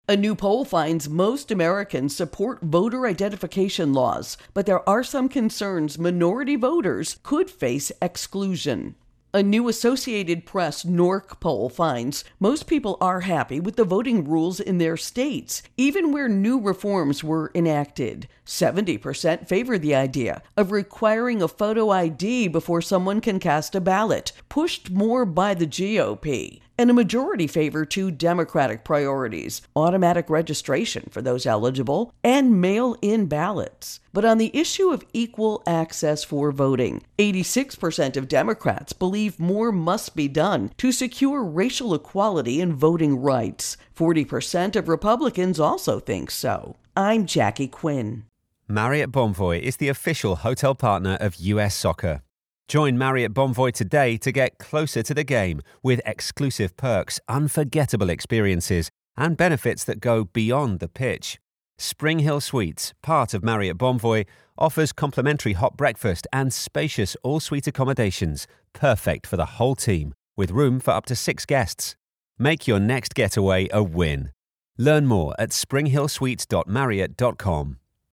AP Poll Voting Rights Intro and Voicer